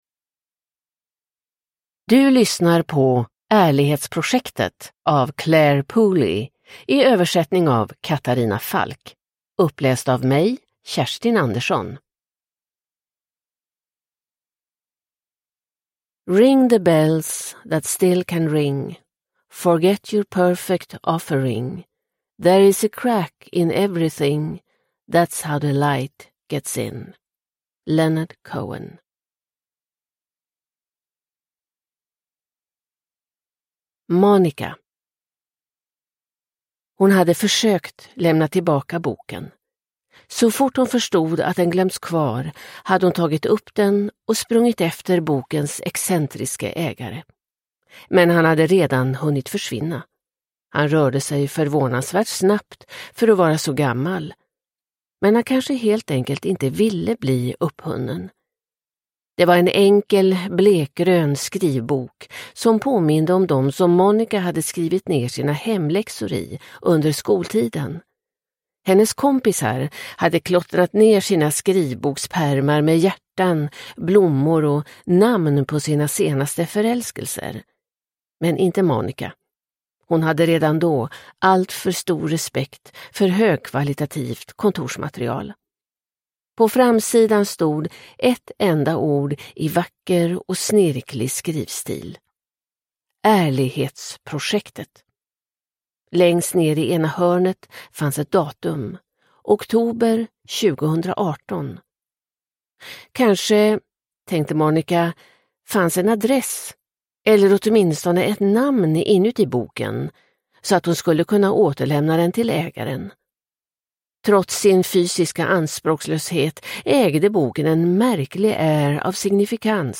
Ärlighetsprojektet – Ljudbok – Laddas ner